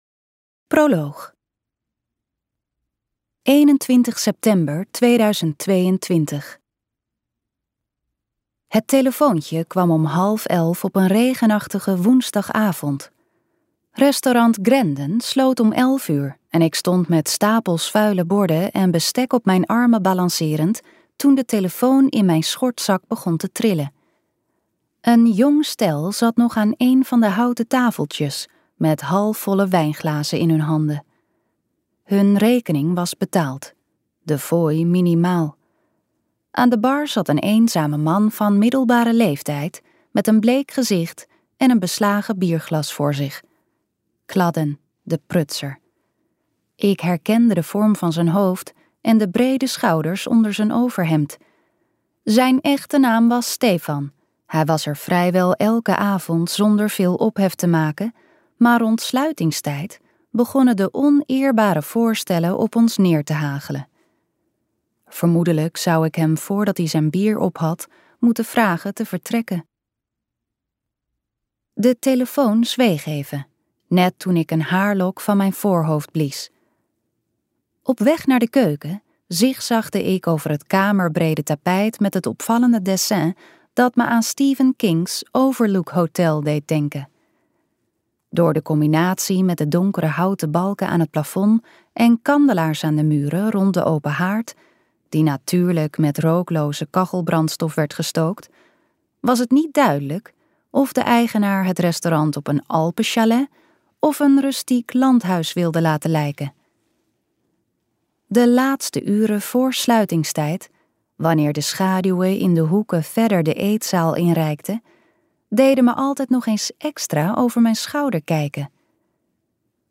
Ambo|Anthos uitgevers - In haar voetsporen luisterboek